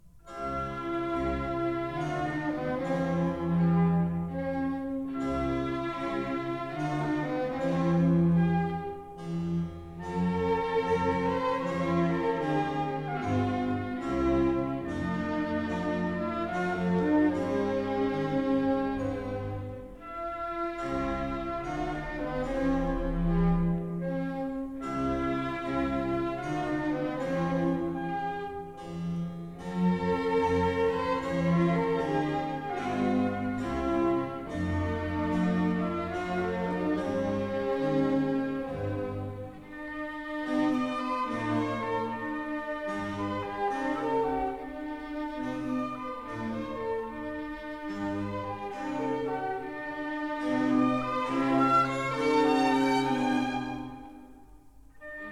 comic opera